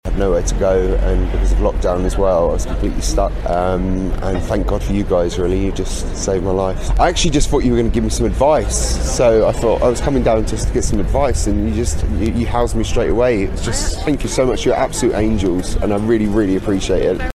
Anonymous Homeless Man helped by Kingston Churches Action on Homelessness